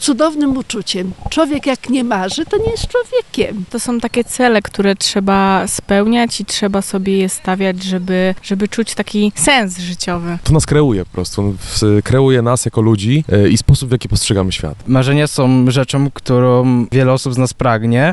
Czym właściwie są marzenia? Zapytaliśmy o to mieszkańców naszego miasta: